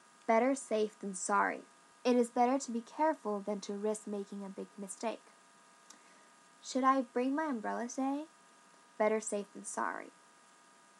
下記のURLより英語ネイティブによる発音が聞けます。